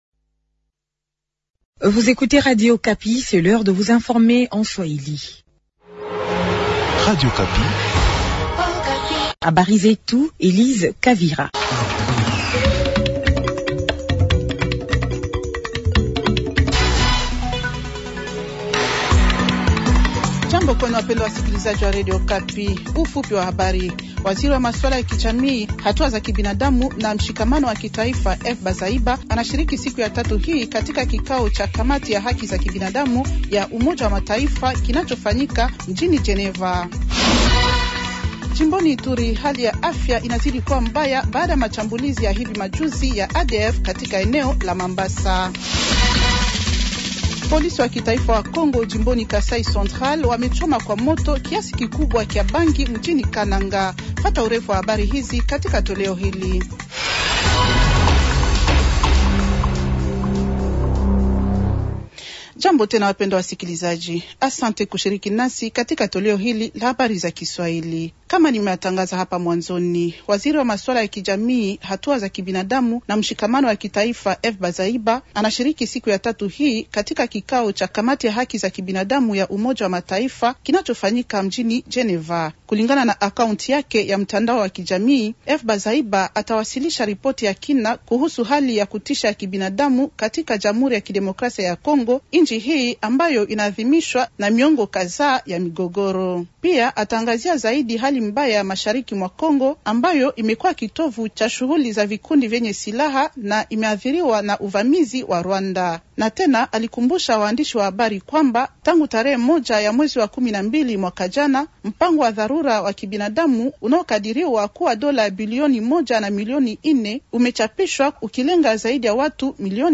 Journal swahili de jeudi matin, 260326